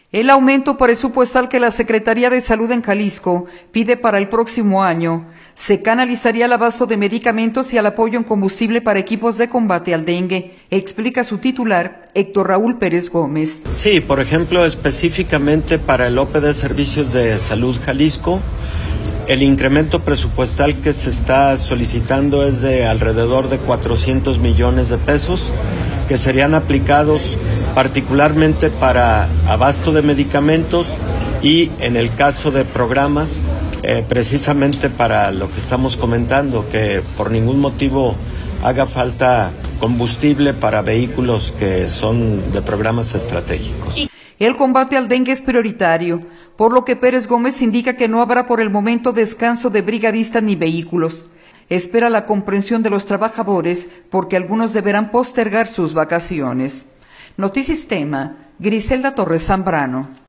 El aumento presupuestal que la Secretaría de Salud en Jalisco pide para el próximo año se canalizaría al abasto de medicamentos y al apoyo en combustible para equipos de combate al dengue, explica su titular, Héctor Raúl Pérez Gómez.